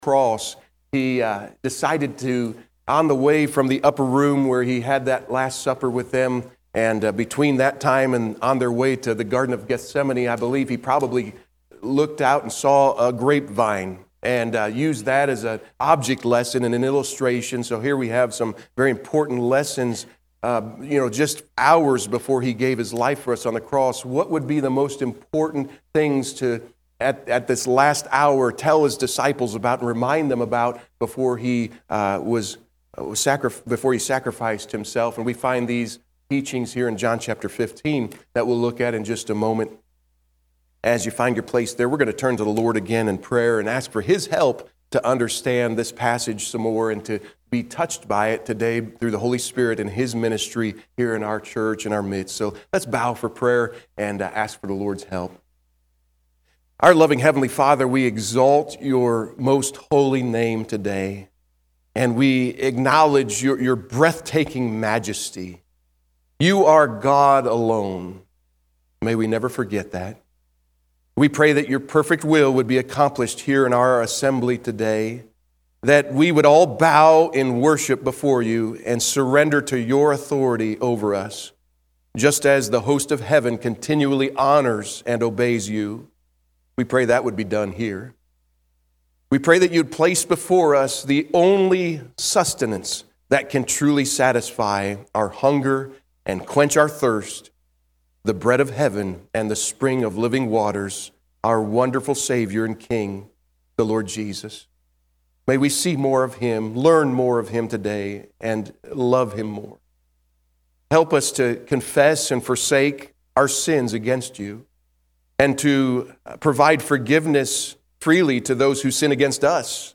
February 23, 2025 – AM Service – Abiding in Christ